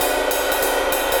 Jazz Swing #3 100 BPM.wav